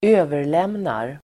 Uttal: [²'ö:ver_lem:nar]